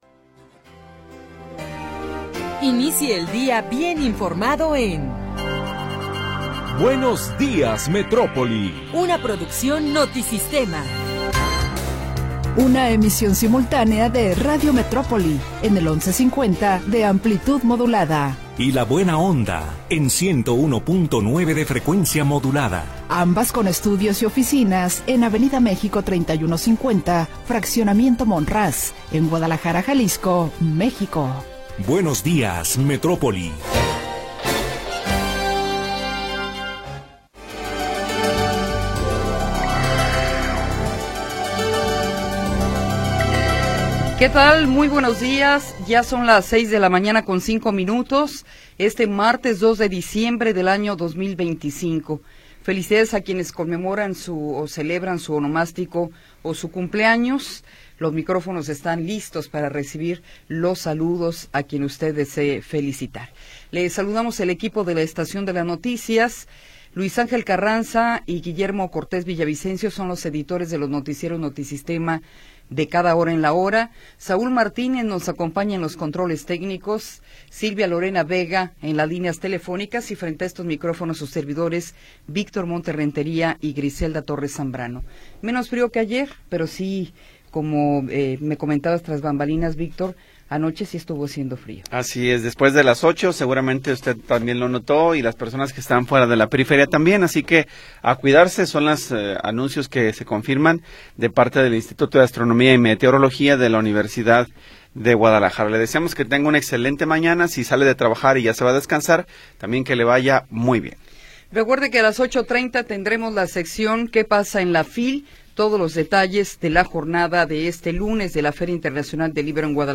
Información oportuna y entrevistas de interés
Primera hora del programa transmitido el 2 de Diciembre de 2025.